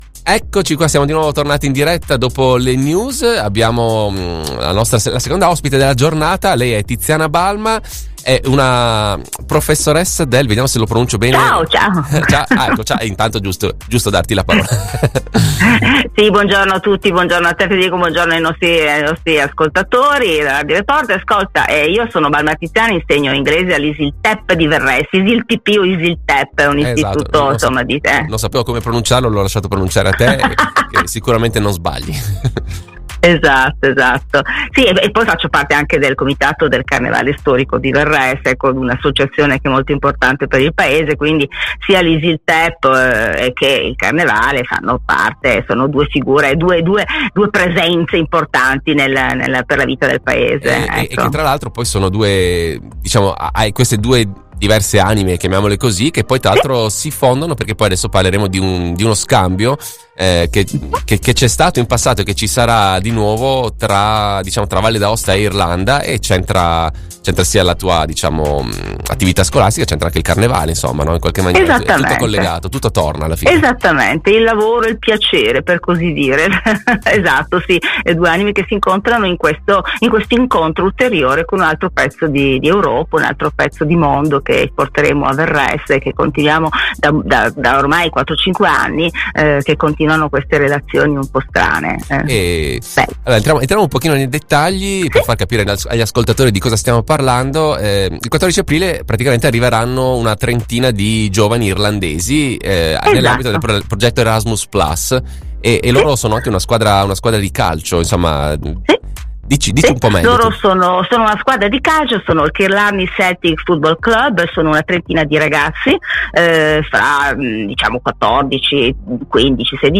Riascoltate la diretta del 9 Aprile